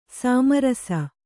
♪ sāma rasa